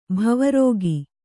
♪ bhava rōgi